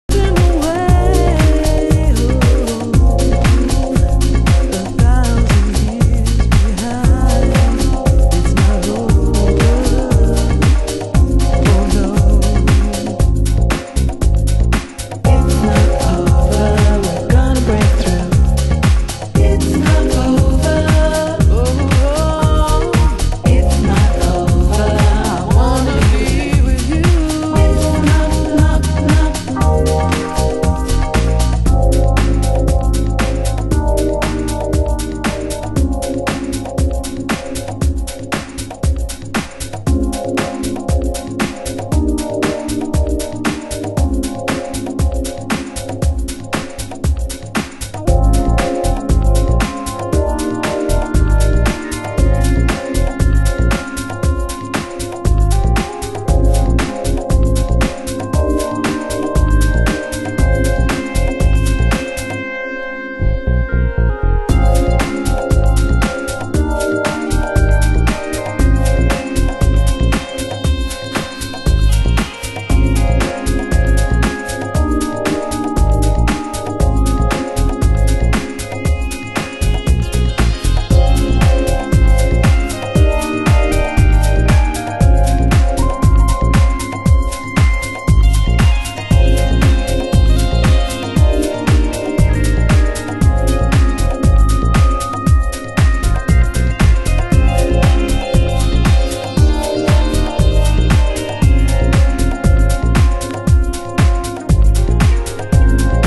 盤質：少しチリパチノイズ有　　ジャケ：良好